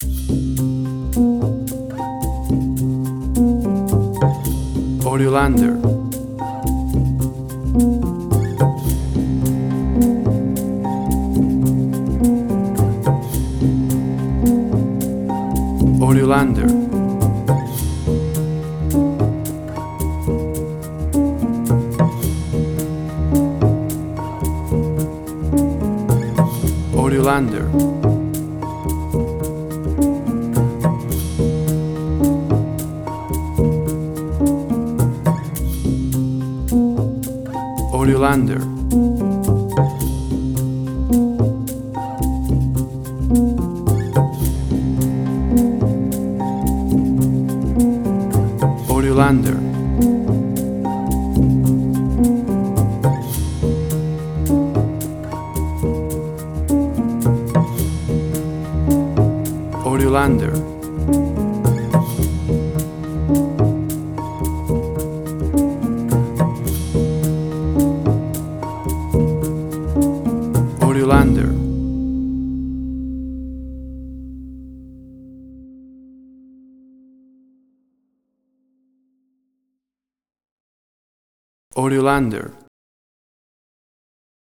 Tempo (BPM): 107